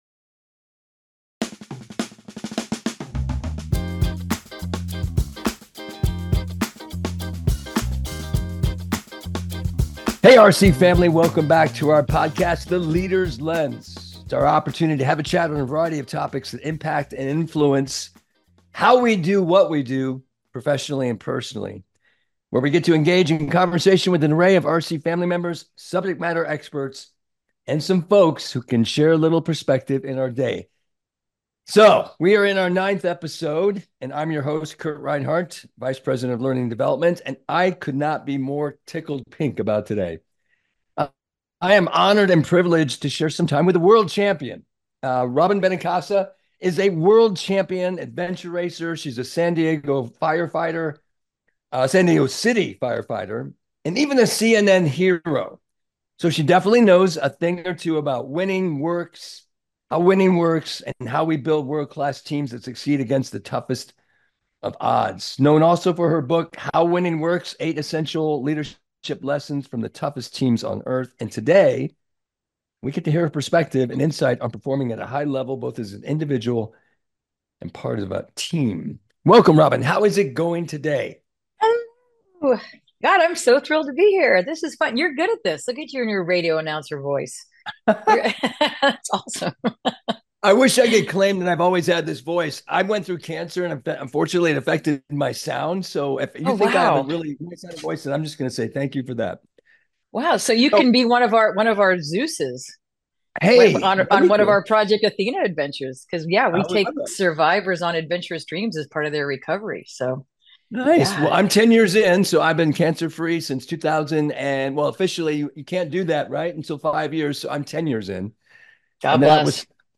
This podcast episode of 'The Leader's Lens' features an interview